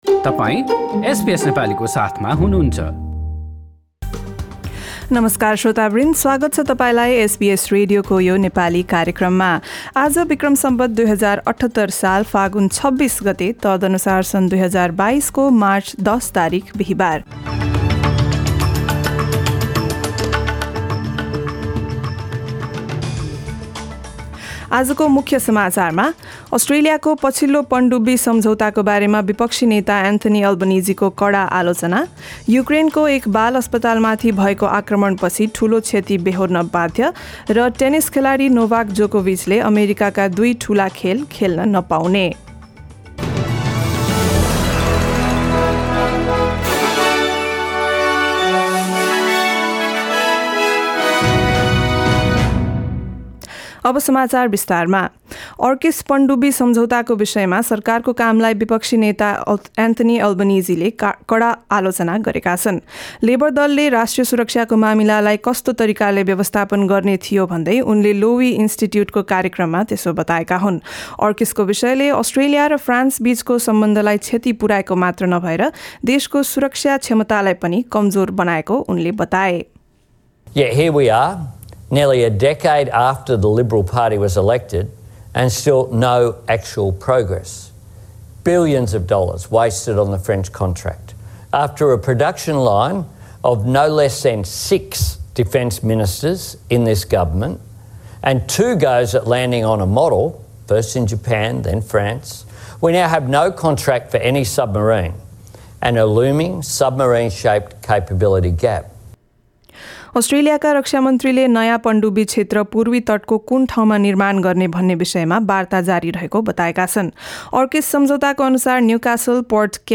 एसबीएस नेपाली अस्ट्रेलिया समाचार: बिहीबार १० मार्च २०२२